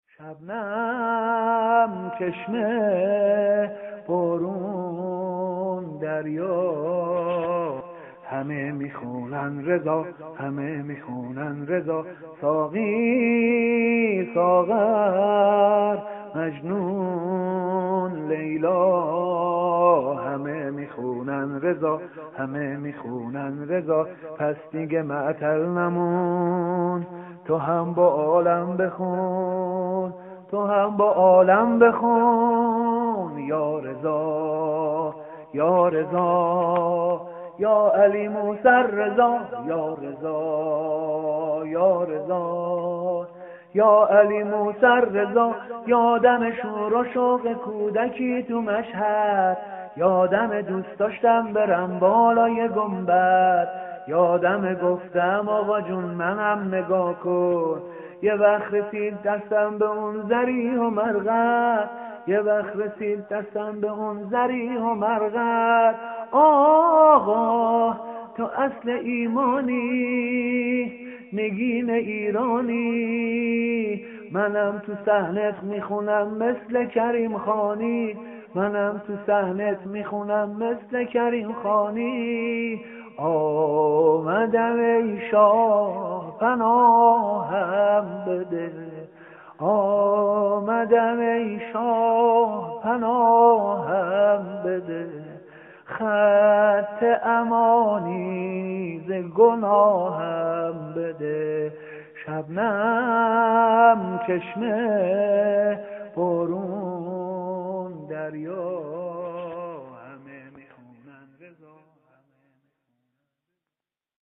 عید غدیر
شور ، سرود